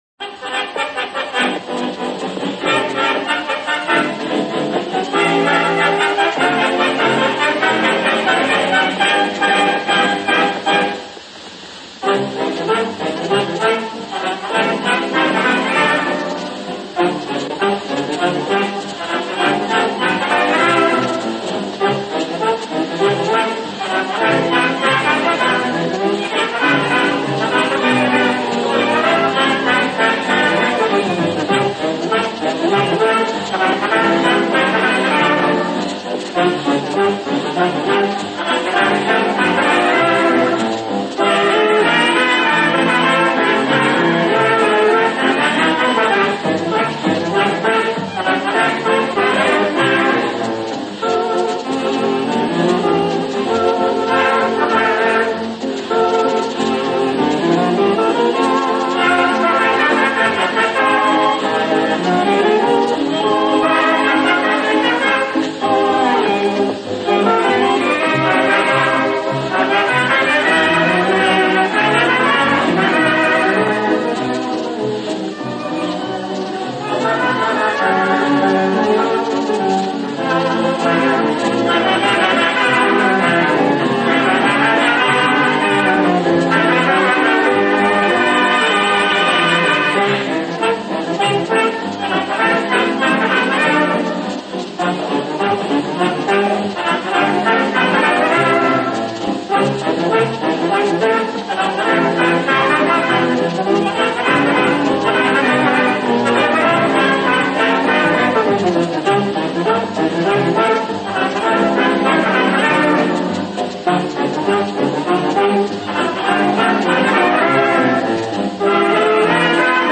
Марши
Описание: Необычно мелодичная для марша, интересная запись.